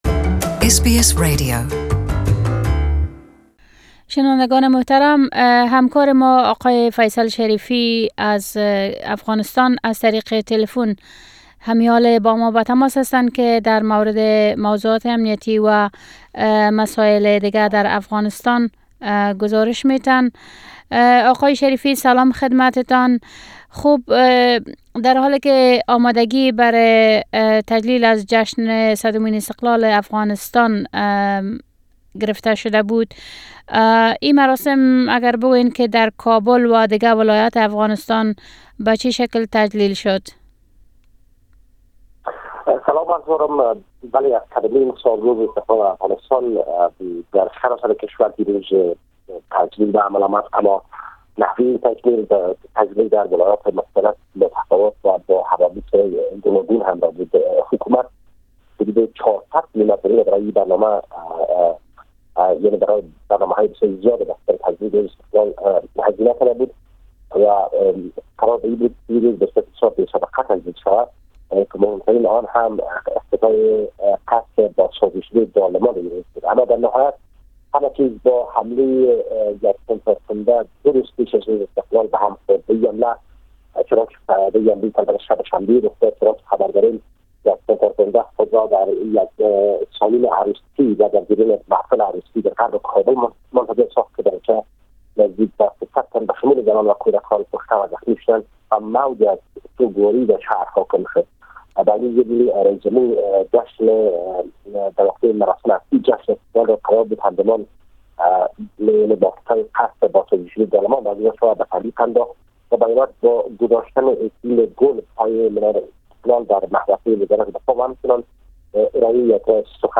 Our correspondent reports from Afghanistan